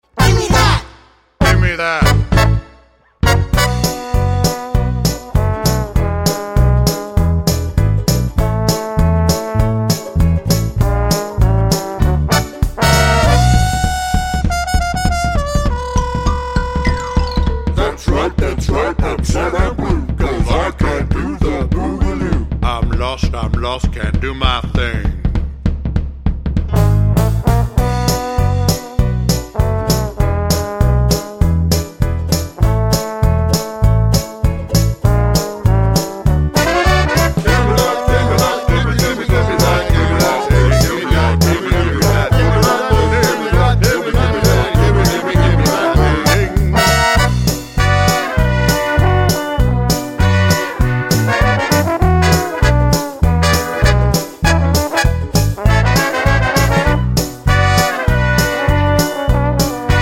no Backing Vocals Comedy/Novelty 2:23 Buy £1.50